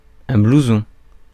Ääntäminen
Ääntäminen France: IPA: [bluzɔ̃] Haettu sana löytyi näillä lähdekielillä: ranska Käännös Substantiivit 1. tuulepluus Suku: m .